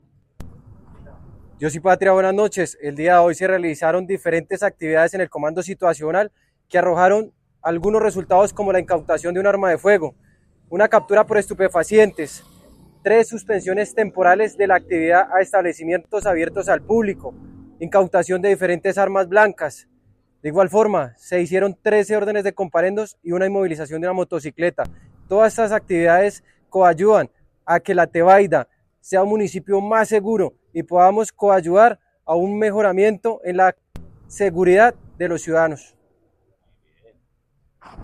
Audio del Comandante de Policía de la Tebaida – Manuel Devia.
MANUEL_DEVIA_RESULTADOS_OPERATIVOS_LATEBAIDA-2.mp3